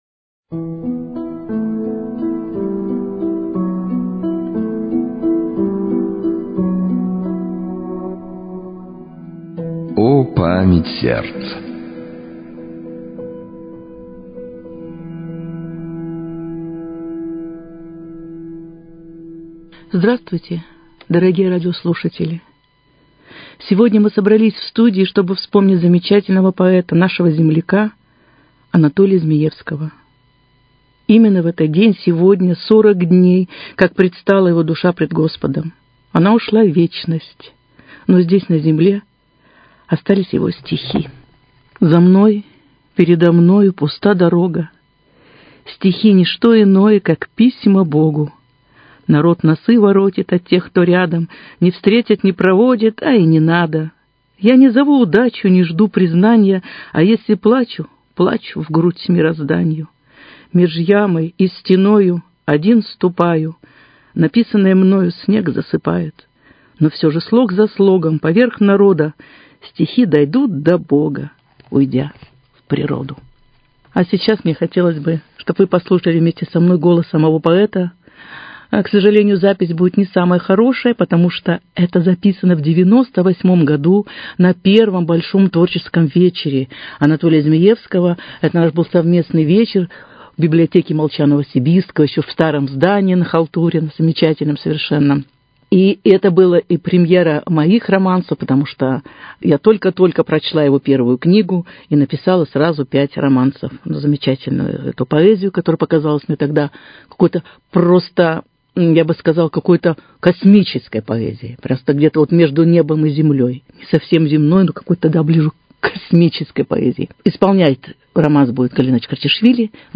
17 марта 66 лет со дня рождения выдающего поэта, нашего земляка Анатолия Змиевского. Вы услышите передачи разных лет, посвящённые его творчеству.